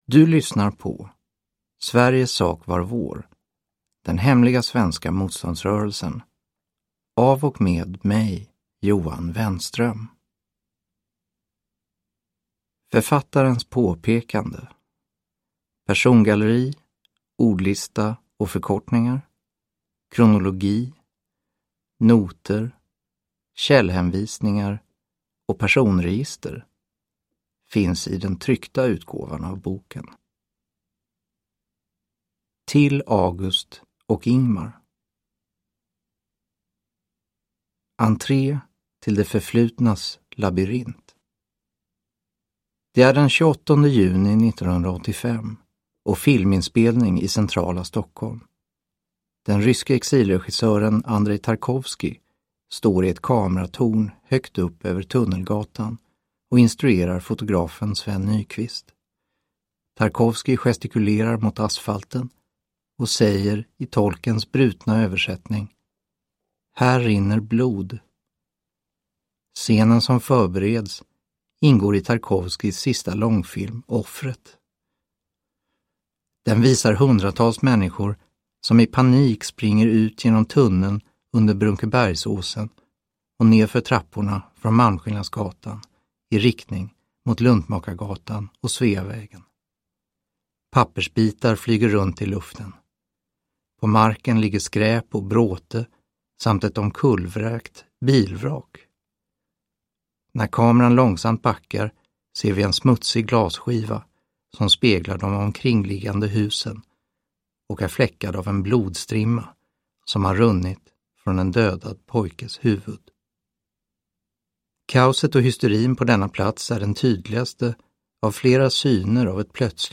Sveriges sak var vår : den hemliga svenska motståndsrörelsen – Ljudbok